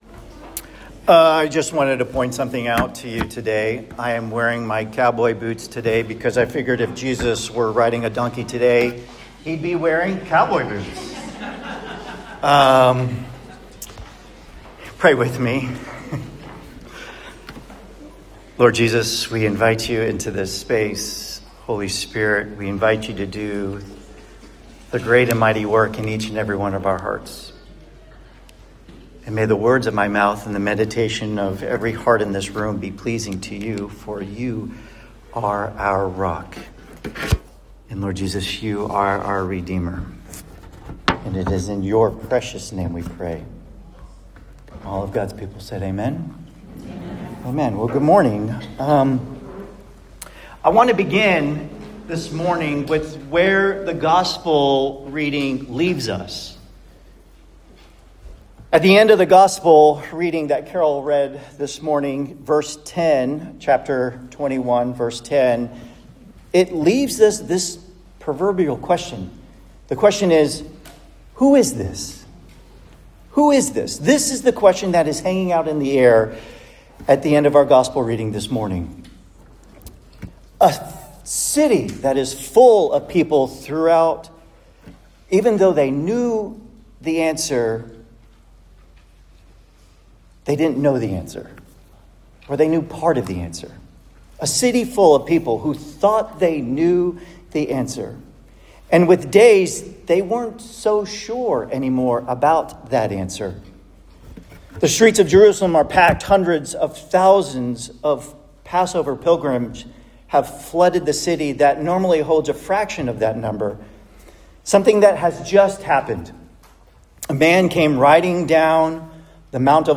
Palm-Sunday-sermon.m4a